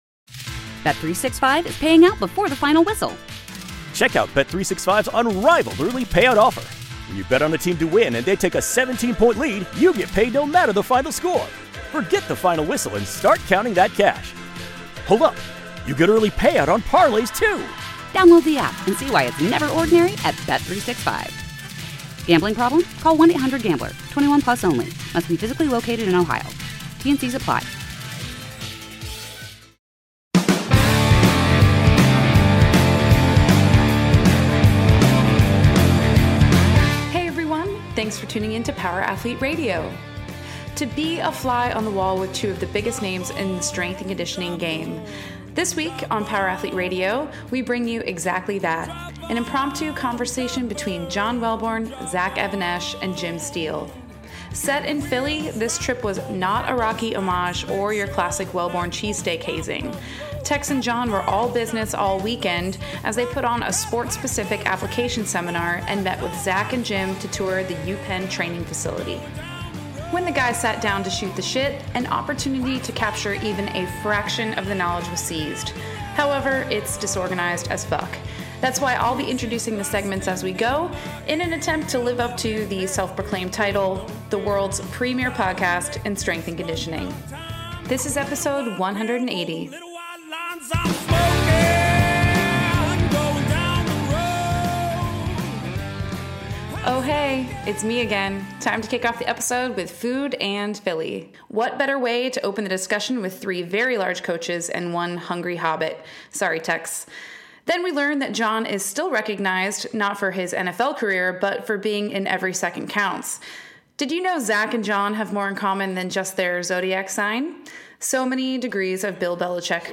an impromptu conversation